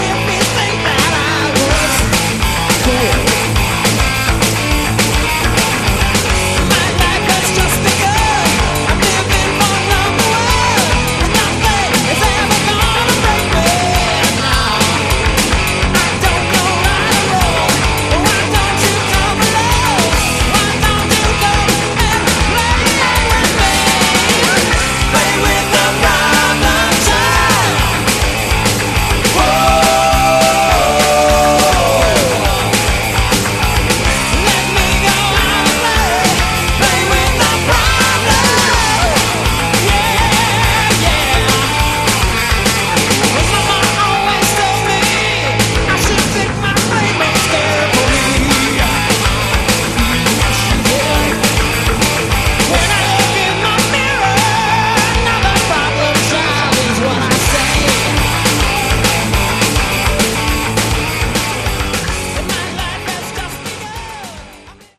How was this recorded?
Originally recorded in 1988